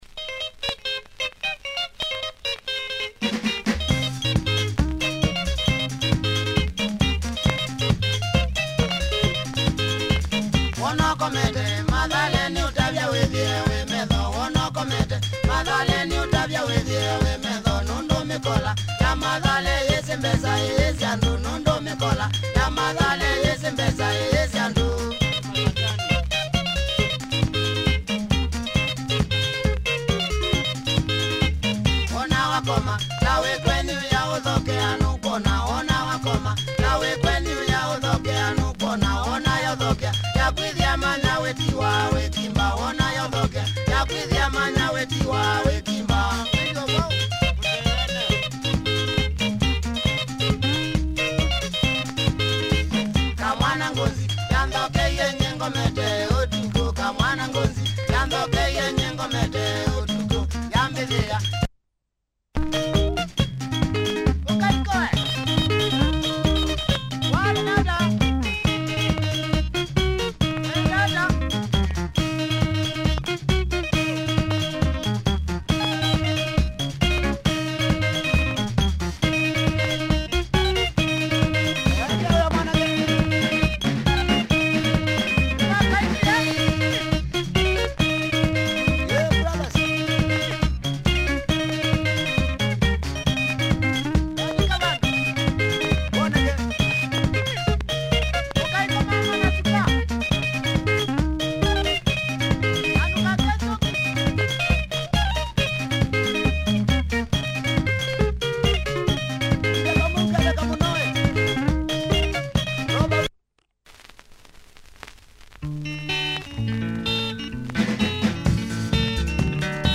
Nice Kamba benga, good tempo, riffin guitar, good stepper!